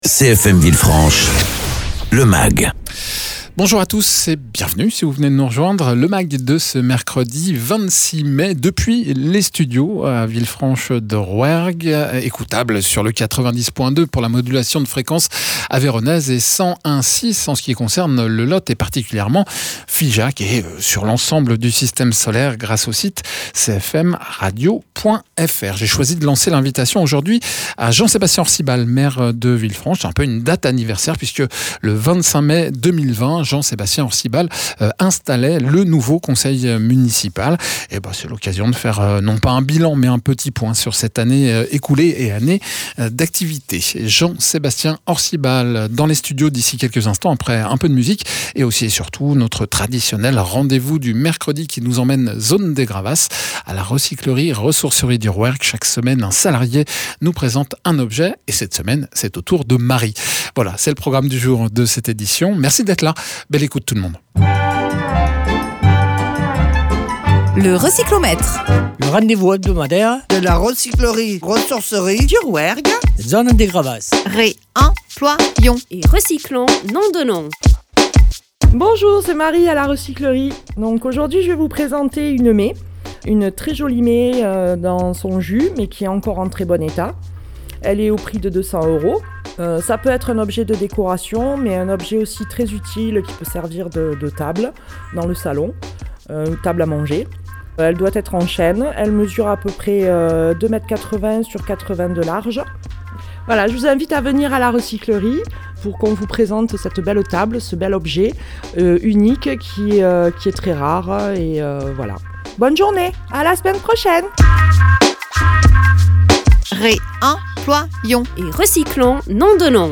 Jean-Sébastien Orcibal, maire de villefranche de Rouergue